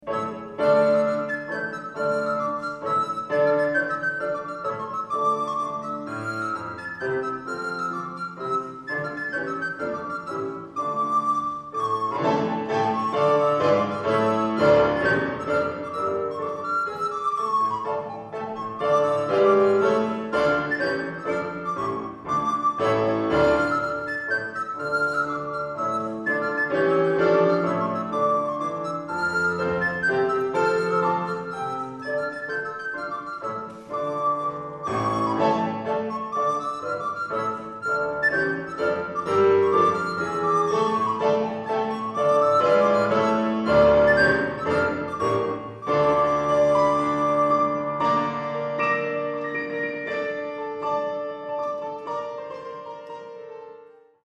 Klavier, Gesang
Blockflöte, Gesang
Sopransaxophon, Gesang